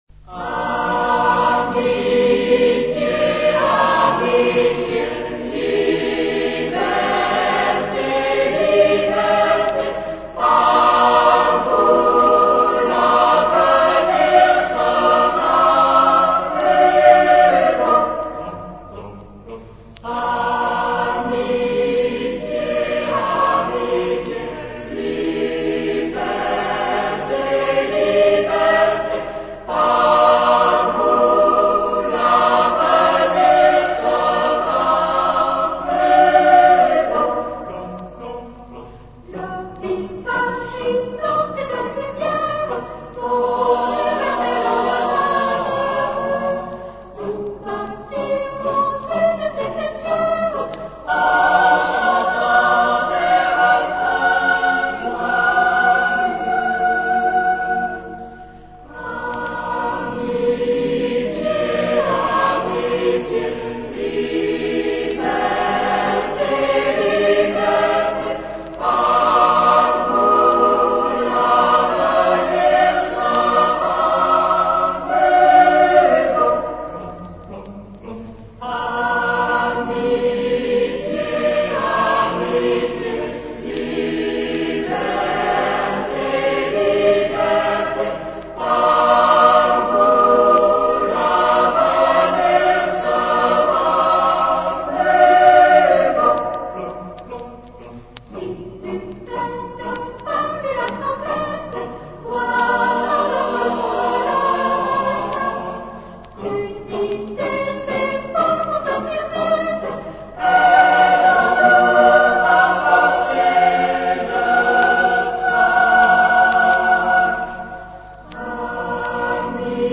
Genre-Style-Forme : Profane ; Chanson de marche
Type de choeur : SATB div  (4 voix mixtes )
Tonalité : si bémol majeur